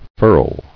[furl]